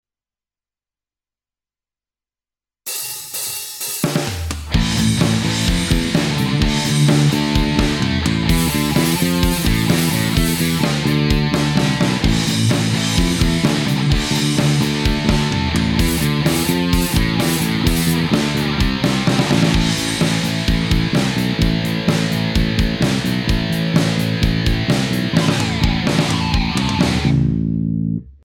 Эпифон лес пол с родным бриджевым